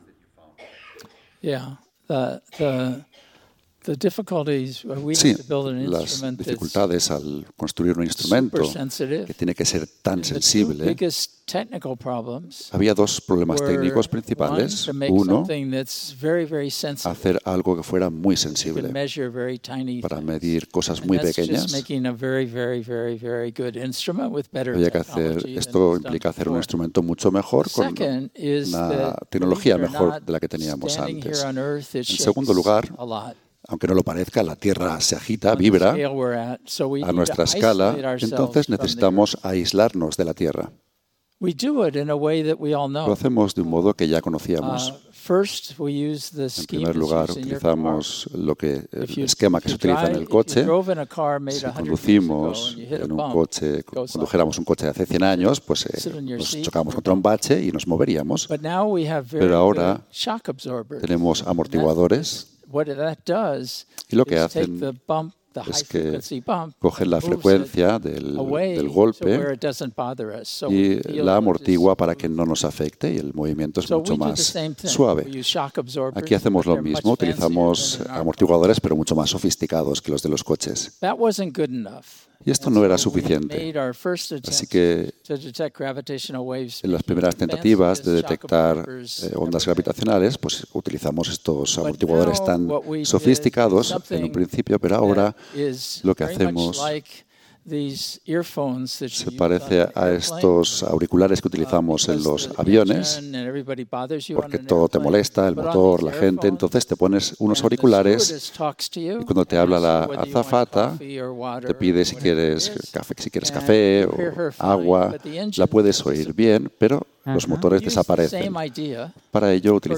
Muestra de trabajos de traducción simultánea que hemos realizado recientemente.
interpretacion-simultanea-entrevista-Barry-Barish.mp3